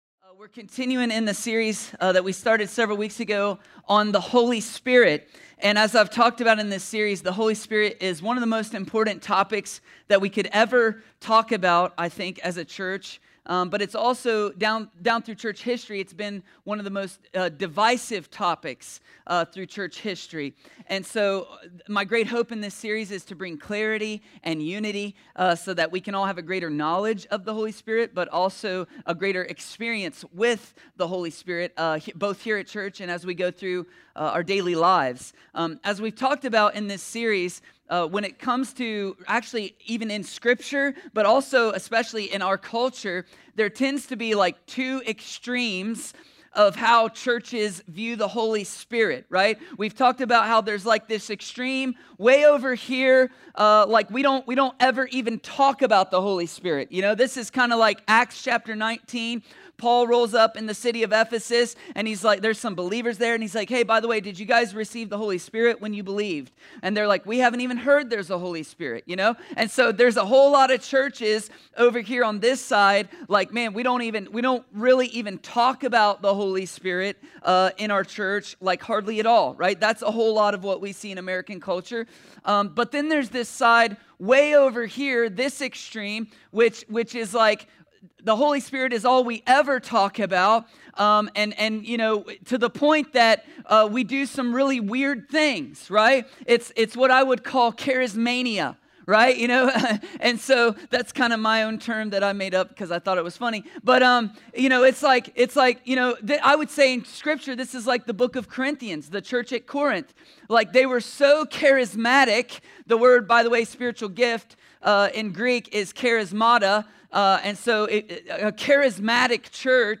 A sermon from the series “The Holy Spirit.”…